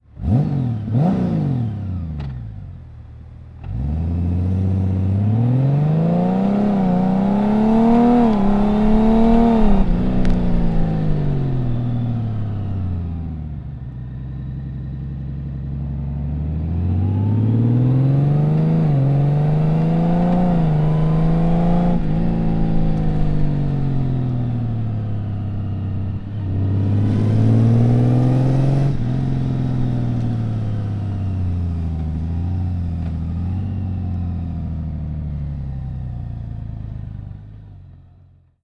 Ecouter le son : Silencieux Remus valve fermée
Honda_Gold_Wing_REMUS_valvefermee.mp3